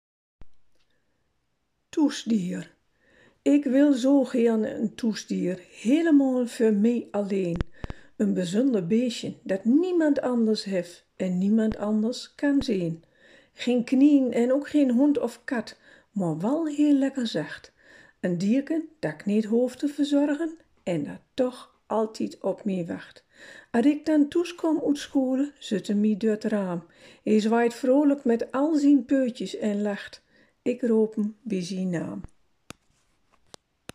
De tekst op de posters is ook ingesproken!